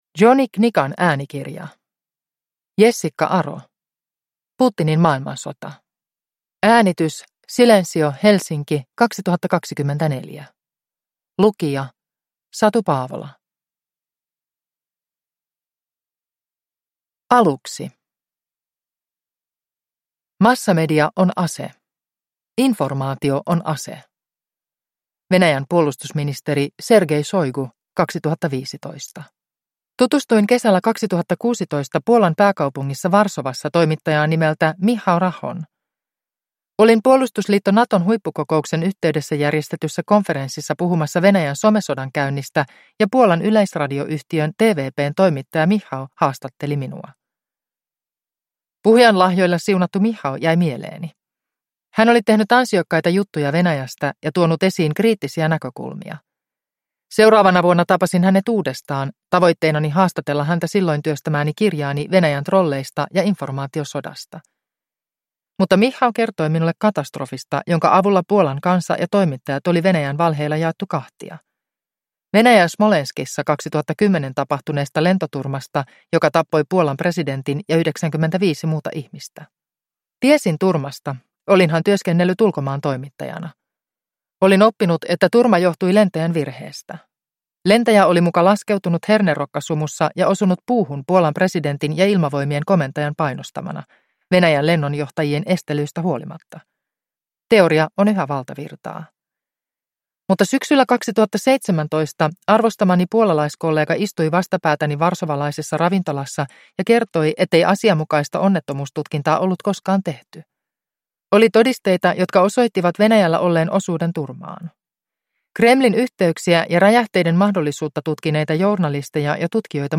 Putinin maailmansota – Ljudbok